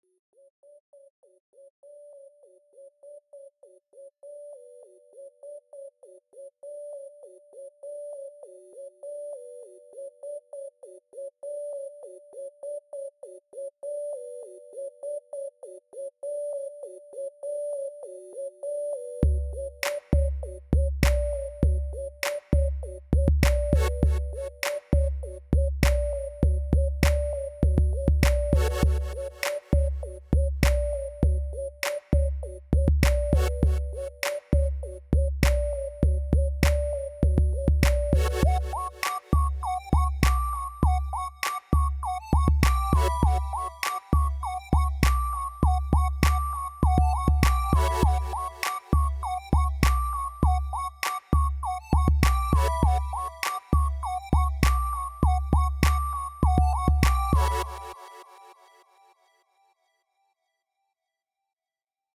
CrunkUpdaClubDemo.mp3 📥 (2.37 MB)